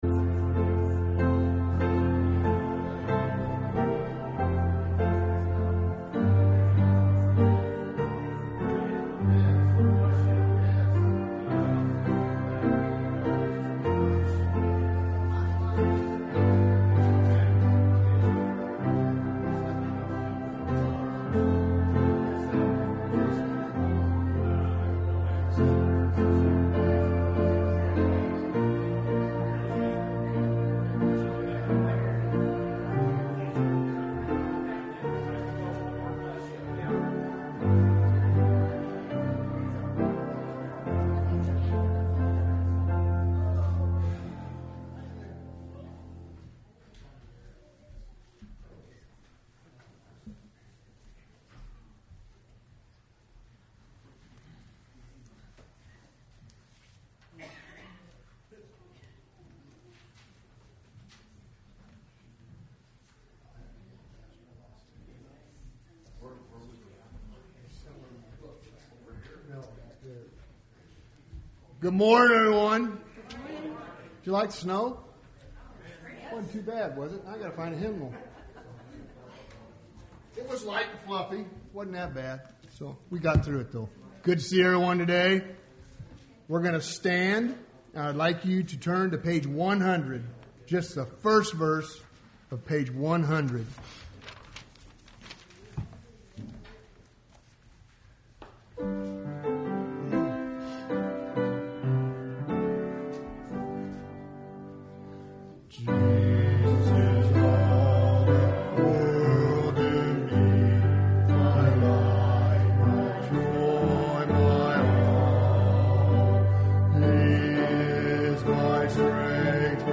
Service Type: Sunday Morning Service